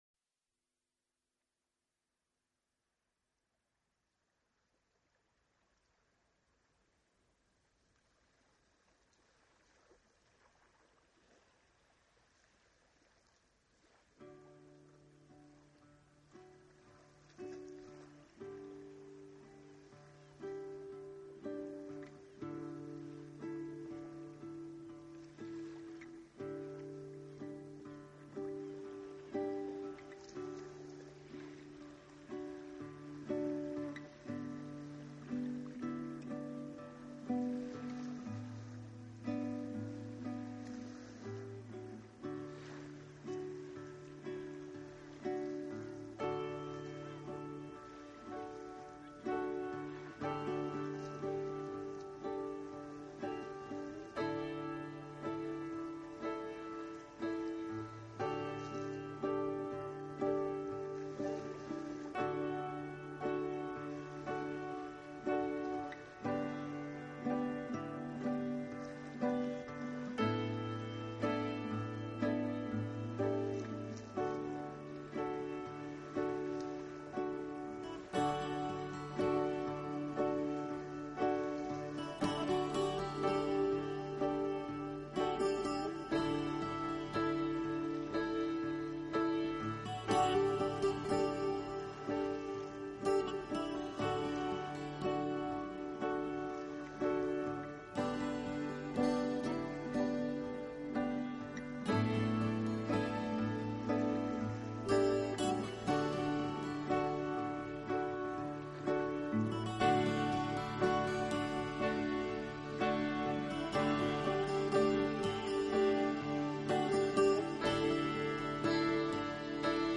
这种音乐是私密的，轻柔的，充满庄严感并总
混合了电子和天空的声音，并将民族元素编入了交响乐结构中以创造其明显而独